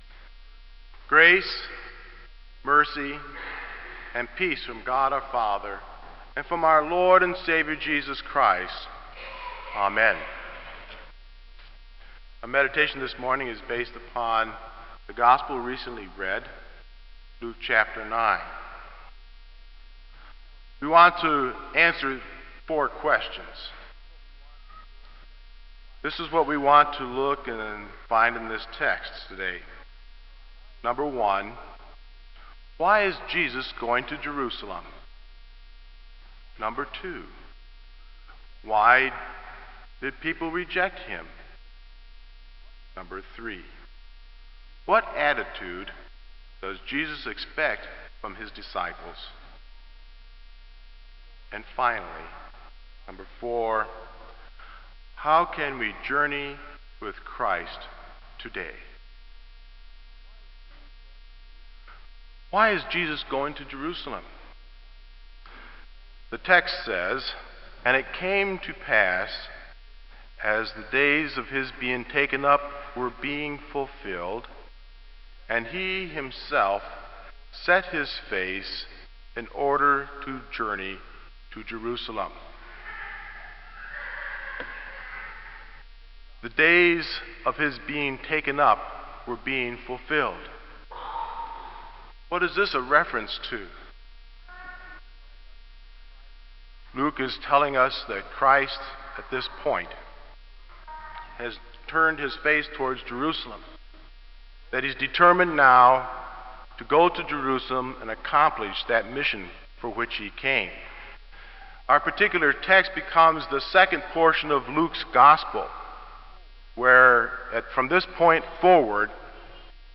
Kramer Chapel Sermon - July 17, 2001